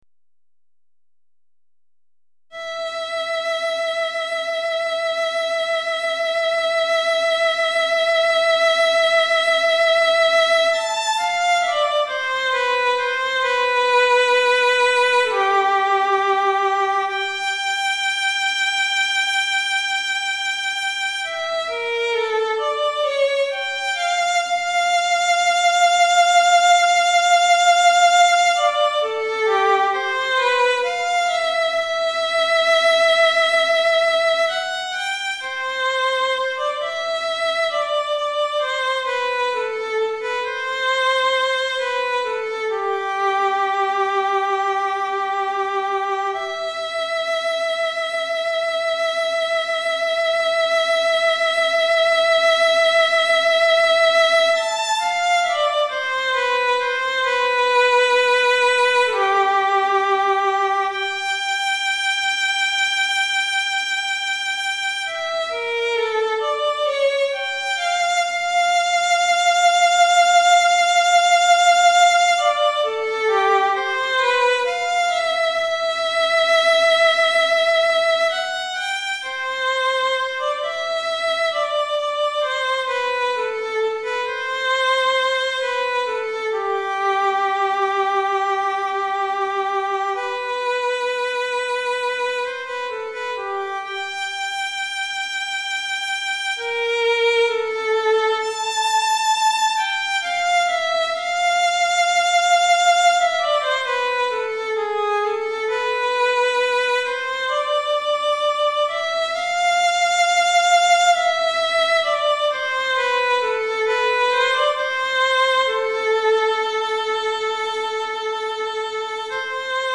Violon Solo